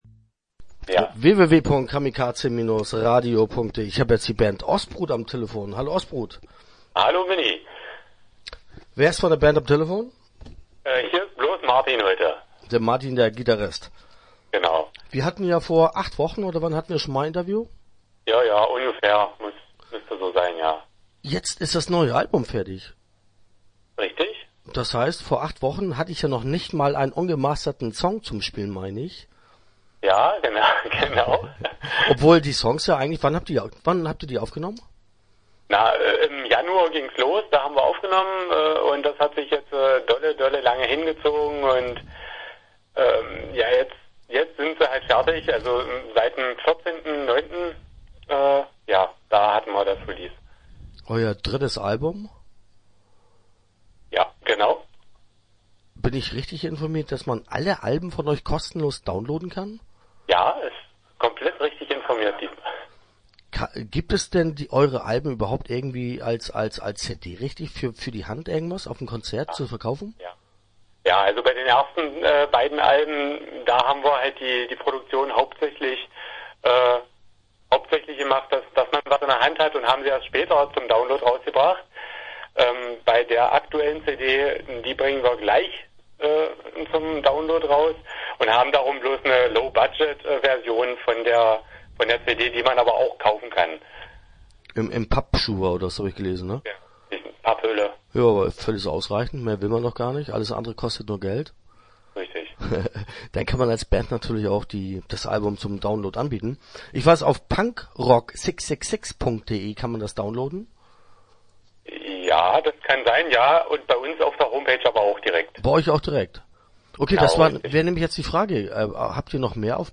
Start » Interviews » Ostbrut